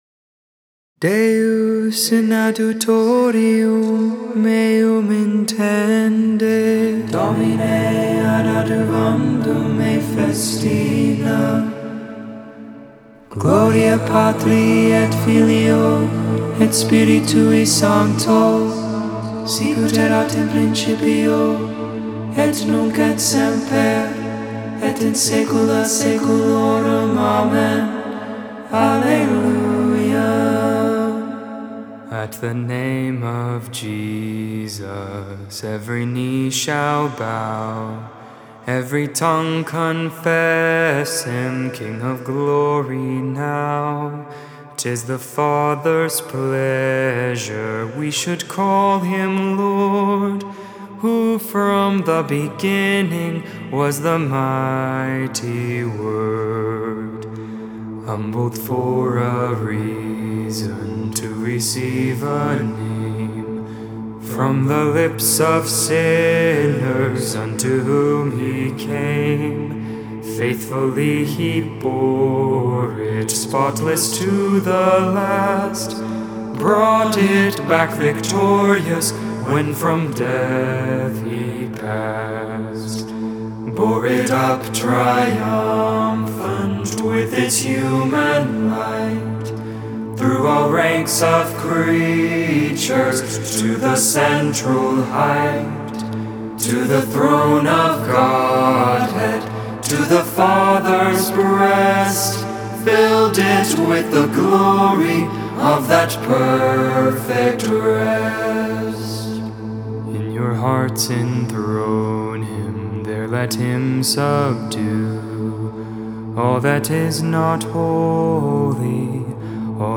Vespers, Evening Prayer for the 5th Saturday in Ordinary Time.